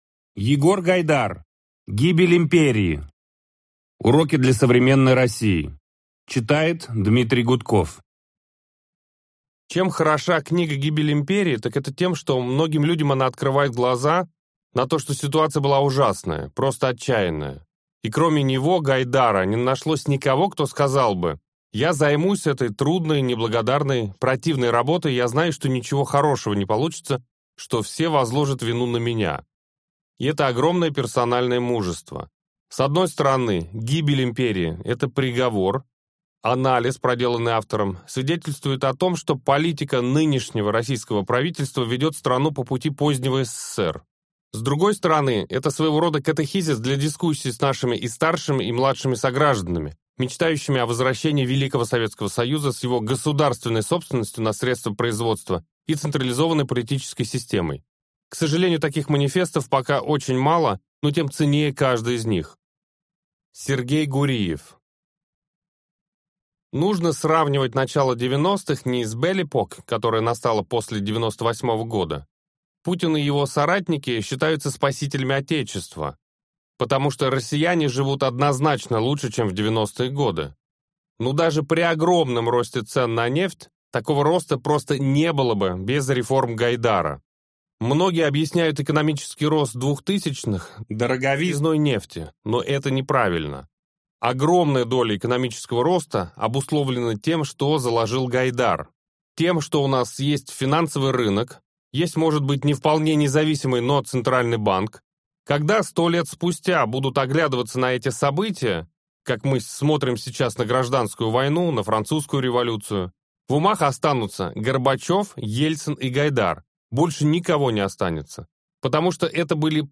Аудиокнига Гибель империи. Уроки для современной России - купить, скачать и слушать онлайн | КнигоПоиск